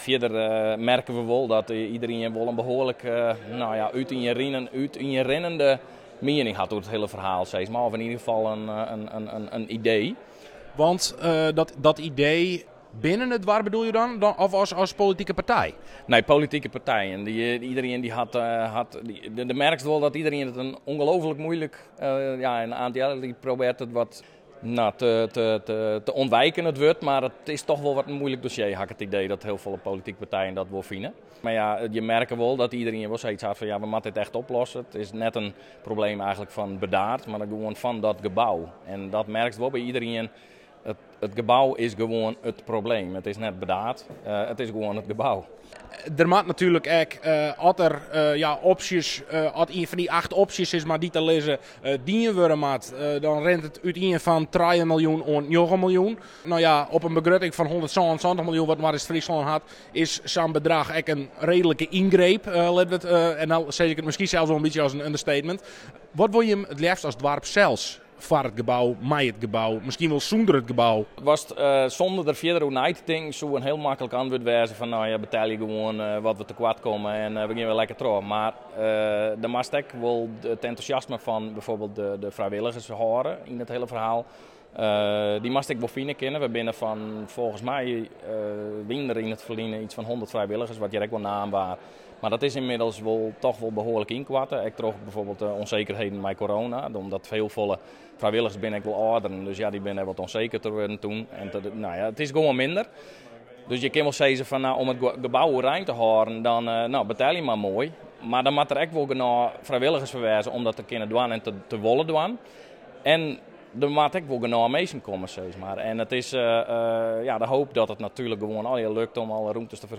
KOLLUM/BURDAARD – In een bomvolle raadszaal, waar donderdagavond tientallen Burdaarders zich hadden verzameld, werd het eerste oriënterende debat over MFC It Spektrum gevoerd.